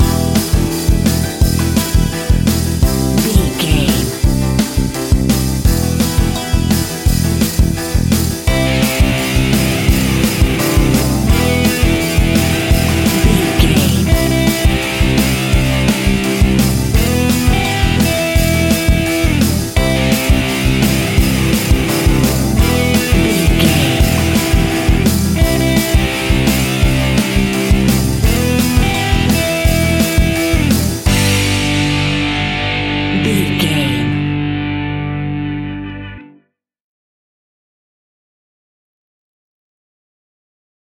A great piece of royalty free music
Epic / Action
Fast paced
Aeolian/Minor
pop rock
indie pop
fun
energetic
uplifting
cheesy
instrumentals
guitars
bass
drums
piano
organ